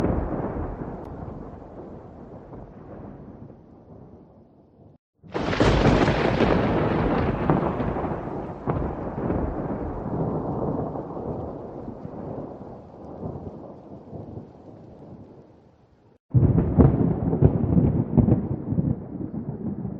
Heavy Rain
Heavy Rain is a free nature sound effect available for download in MP3 format.
331_heavy_rain.mp3